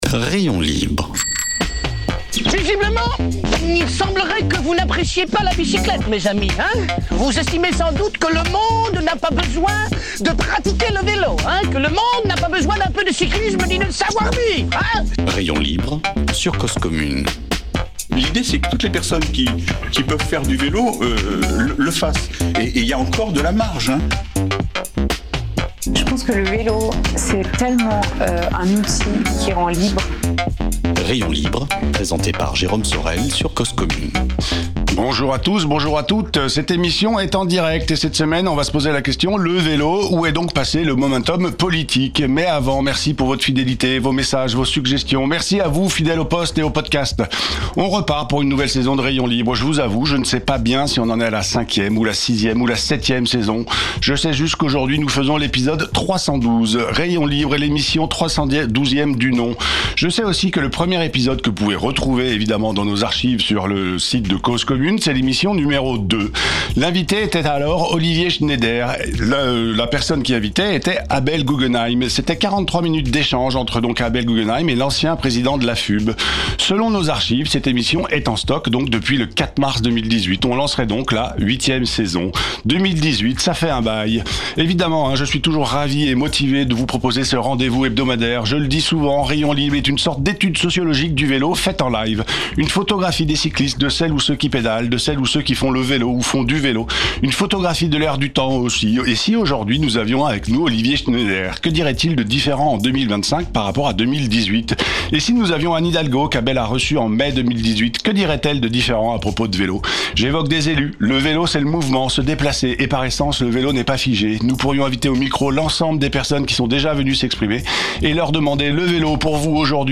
Émission proposée en partenariat avec Weelz!.